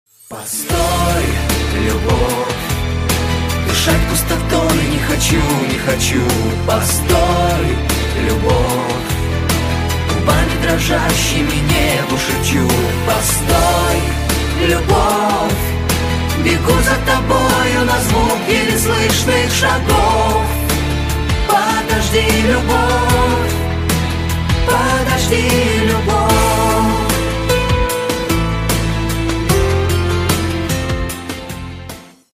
• Качество: 128, Stereo
мужской вокал
громкие
женский вокал
спокойные
клавишные
пианино
медленные
медляк